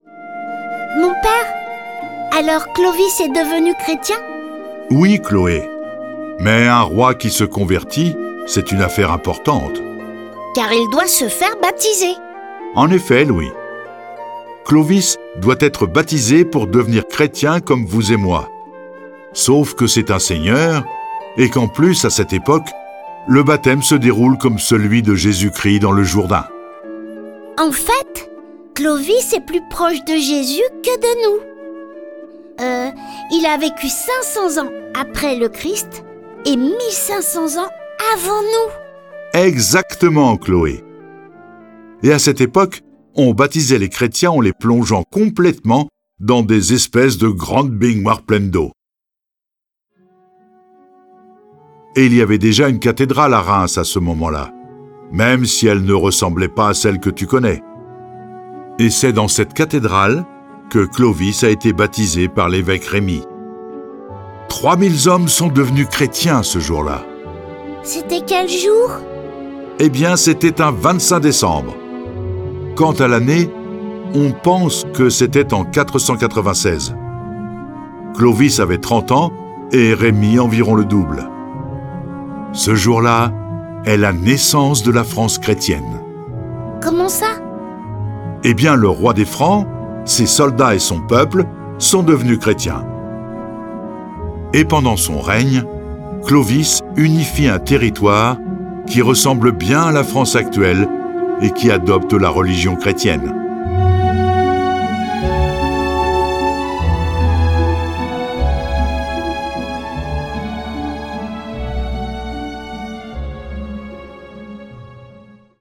Diffusion distribution ebook et livre audio - Catalogue livres numériques
Cette version sonore de la vie de saint Remi est animée par 8 voix et accompagnée de plus de 30 morceaux de musique classique.